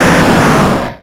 Cri de Kangourex dans Pokémon X et Y.